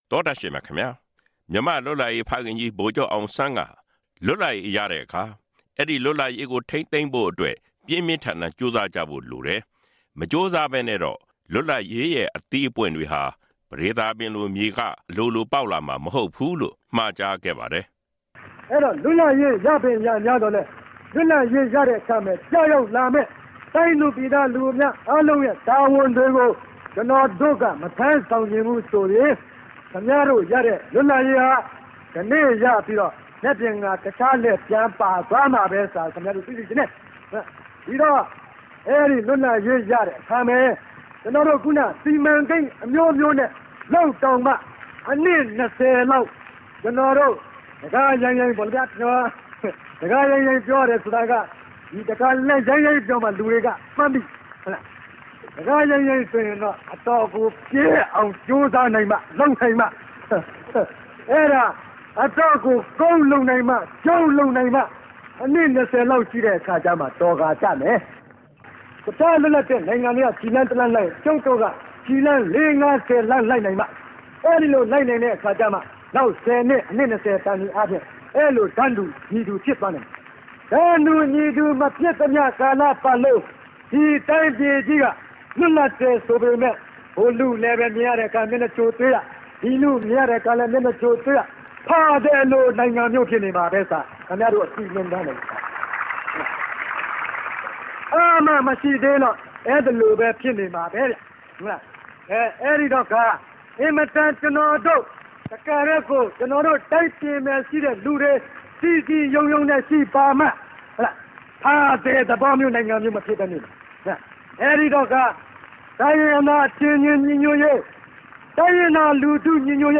မြန်မာ့လွတ်လပ်ရေးဖခင် ဗိုလ်ချုပ်အောင်ဆန်းက ၁၉၄၇ ခုနှစ် ဇူလိုင်လ ၁၃ ရက်နေ့မှာ ရန်ကုန် မြို့တော်ခမ်းမကနေ နောက်ဆုံးပြောကြားခဲ့တဲ့ မိန့်ခွန်းကောက်နှုတ်ချက်တချို့ကို